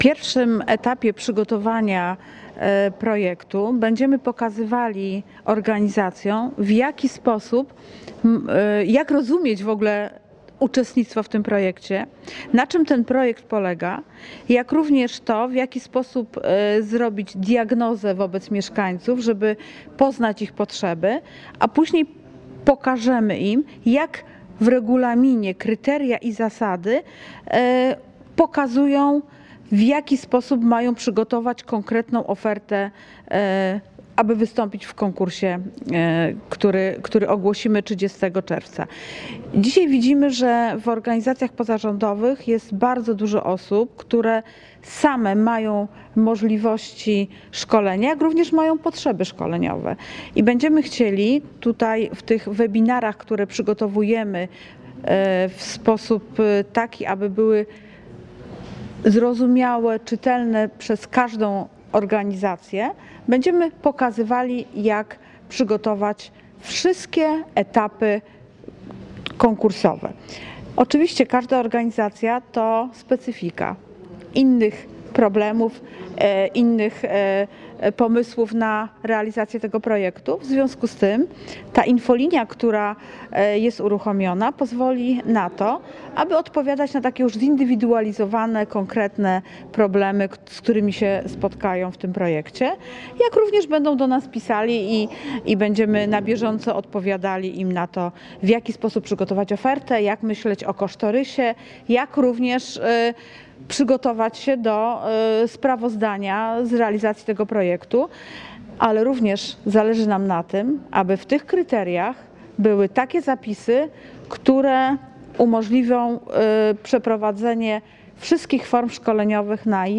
– Europejski Budżet Obywatelski to nieskończone możliwości, to szansa na zdobycie przez mieszkańców regionu nowych umiejętności i kwalifikacji. Właśnie przygotowujemy się do pierwszego otwartego konkursu ofert dla organizacji pozarządowych w ramach tego projektu – mówił marszałek województwa Andrzej Buła na konferencji prasowej.